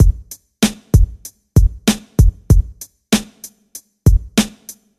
• 96 Bpm 00's Rap Drum Loop F# Key.wav
Free drum loop - kick tuned to the F# note. Loudest frequency: 1243Hz
96-bpm-00s-rap-drum-loop-f-sharp-key-aSP.wav